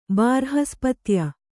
♪ bārhaspatya